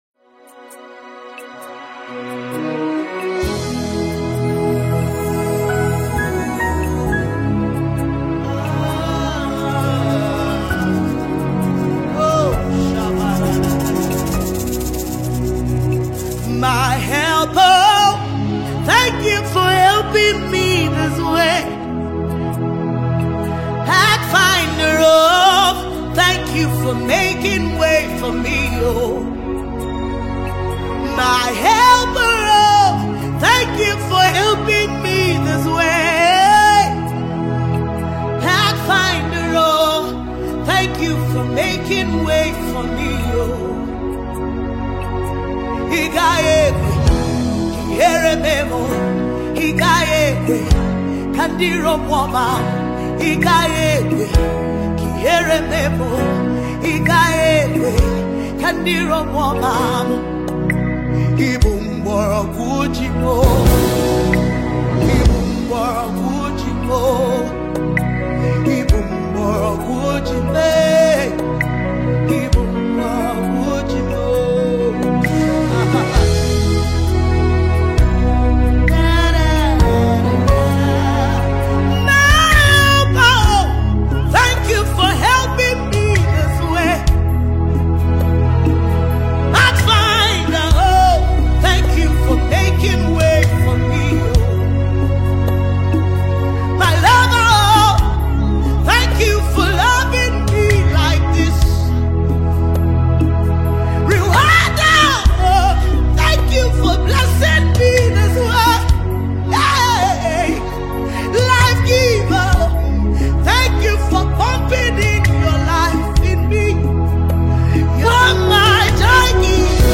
Gospel music
soul-stirring worship anthem